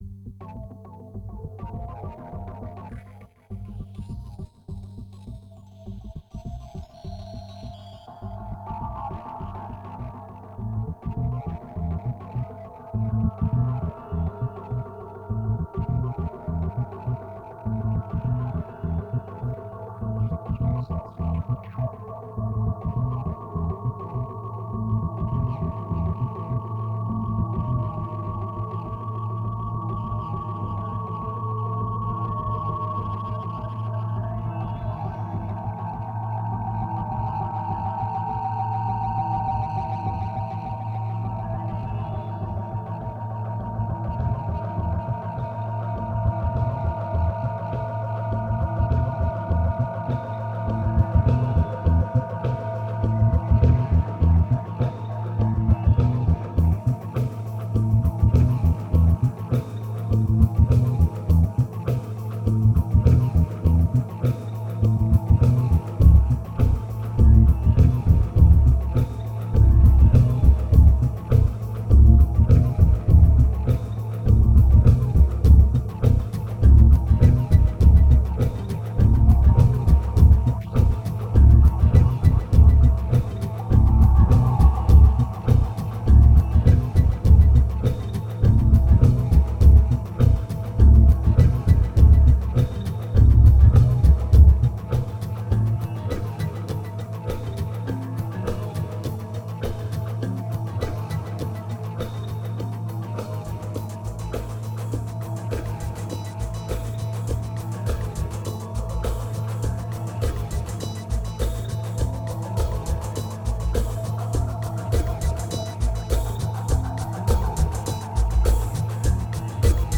2150📈 - 7%🤔 - 102BPM🔊 - 2010-10-31📅 - -150🌟